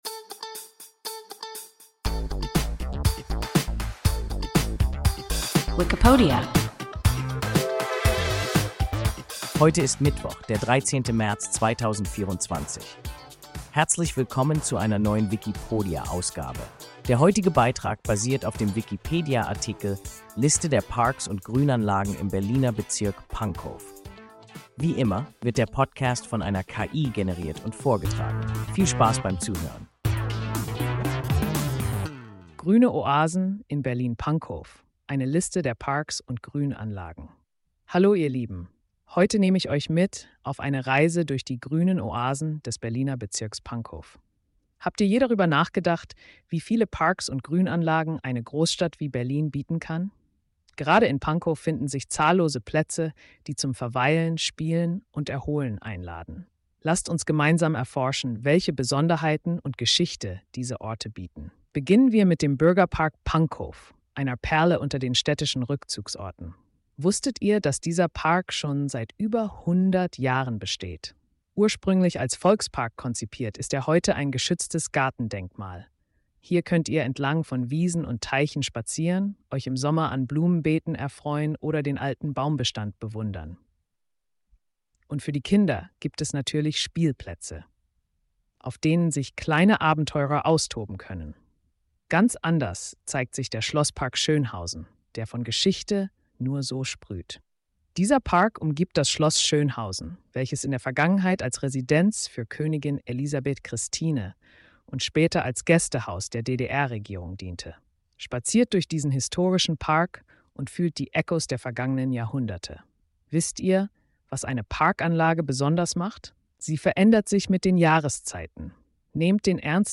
Liste der Parks und Grünanlagen im Berliner Bezirk Pankow – WIKIPODIA – ein KI Podcast